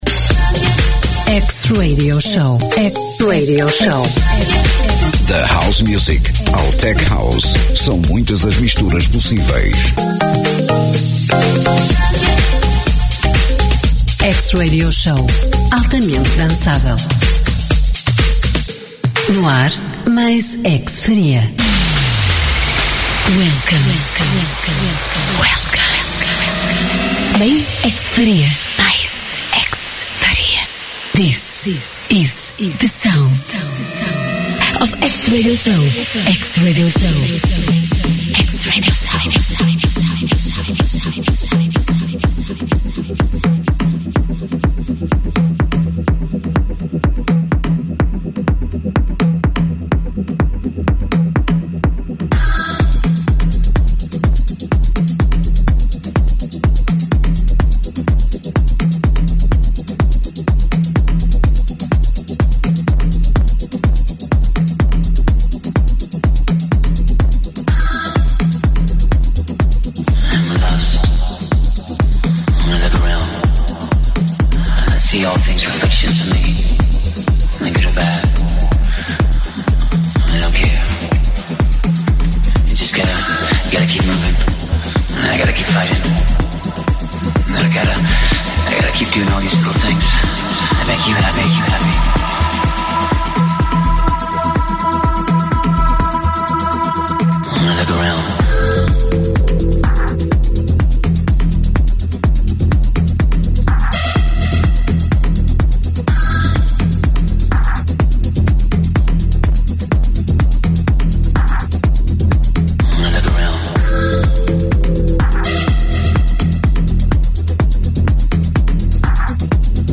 House Music in the mix